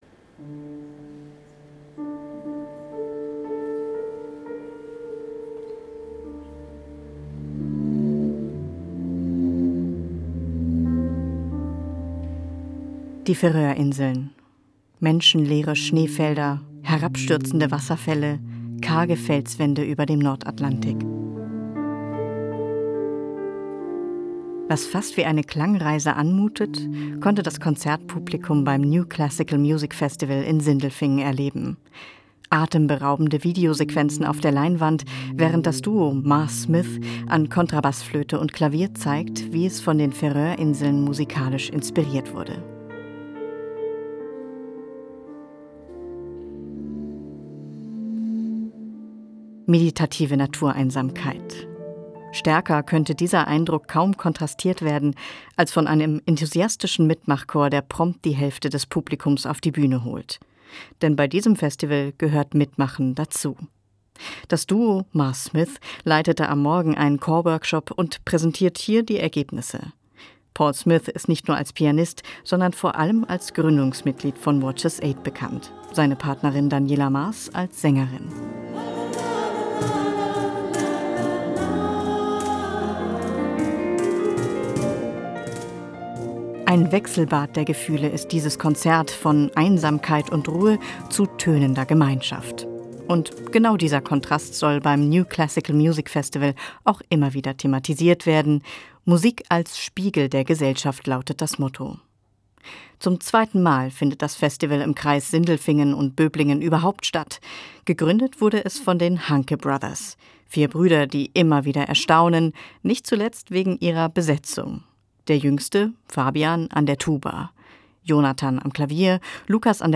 Musikthema
Tuba, Blockflöte, Bratsche und Klavier.